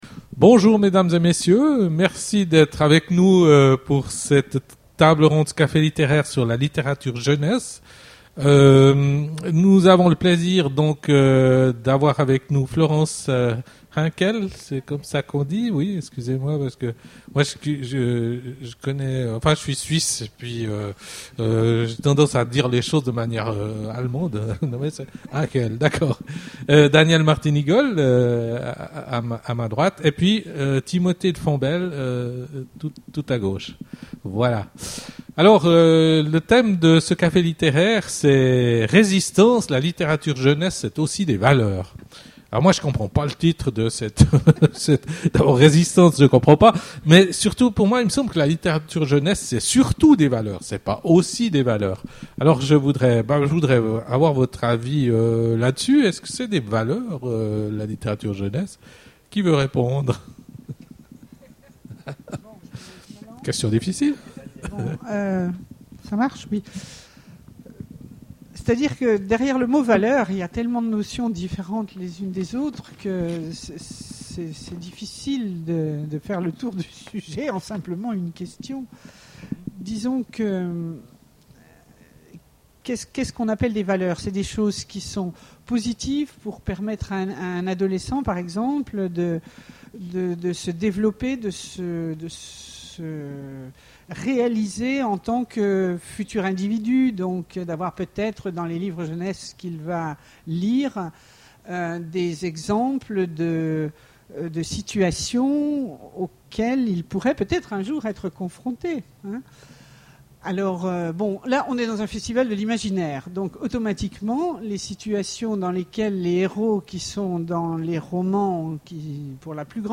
Imaginales 2013 : Conférence Résistances